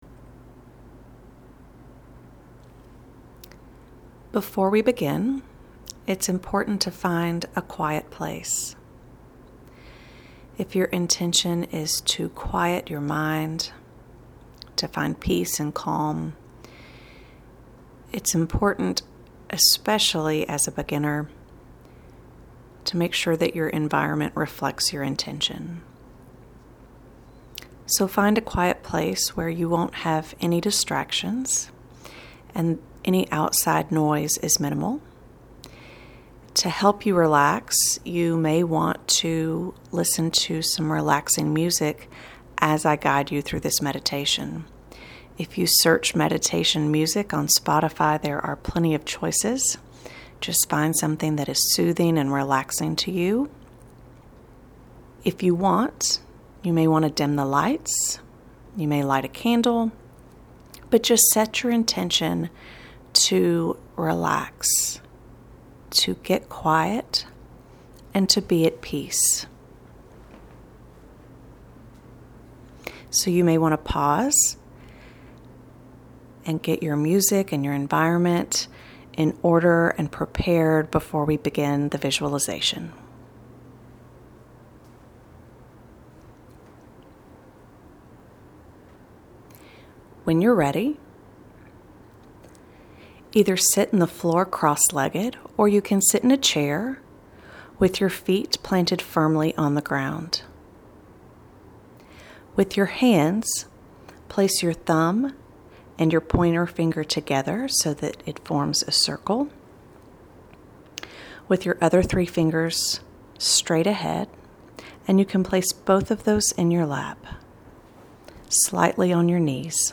I’ve created a guided meditation for you to try.
Guided+meditation+for+newsletter+from+fog+to+light.mp3